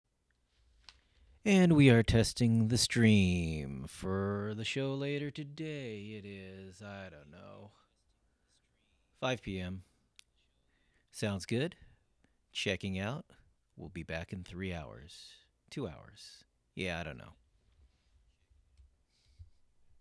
ARCHIVE Live from the Thingularity Studios: Thingularity (Audio) May 04, 2023 shows Live from the Thingularity Studios LIVE from NIMBY Heights Catskill Play In New Tab (audio/mpeg) Download (audio/mpeg)